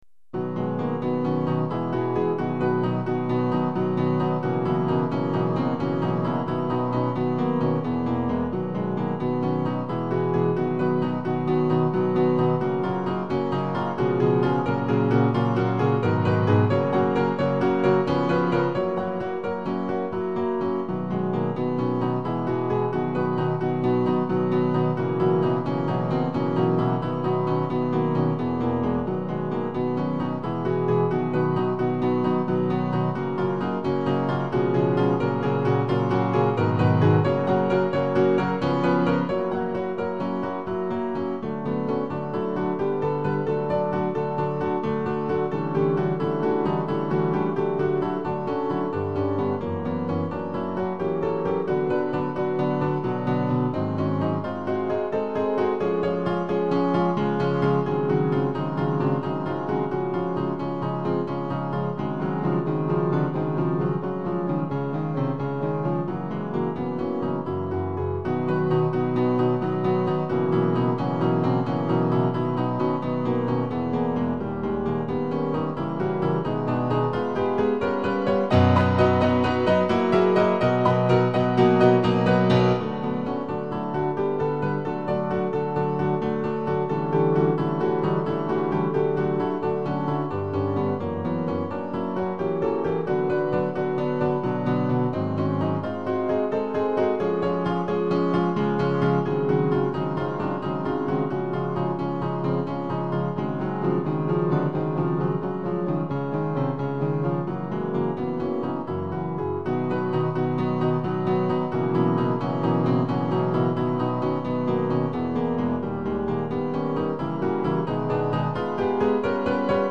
クラシックピアノから私のお気に入りをmidiで打ち込んでみました。
全体を通して殆どが八分音符ですが、メロディーが非常に秀逸で完璧な移調には感服するばかりです。そしてラストの音の洪水も聴き所。